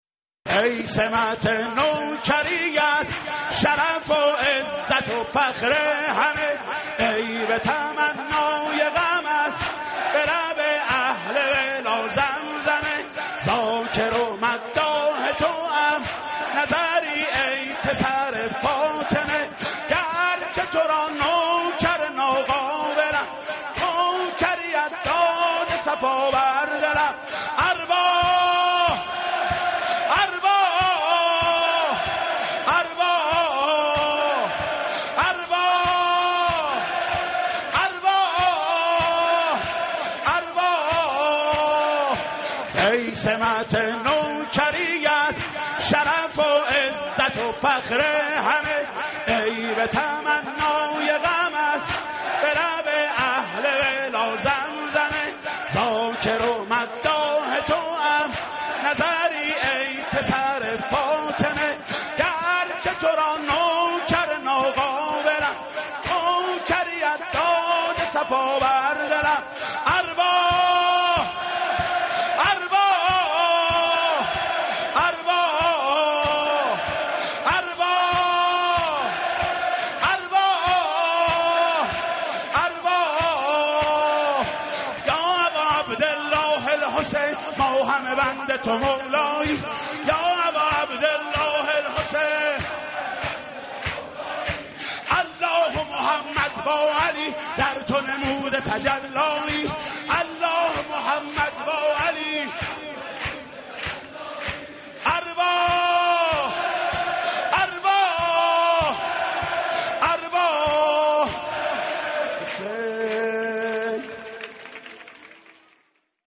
مولودی ویژه ولادت امام حسین علیه‌السلام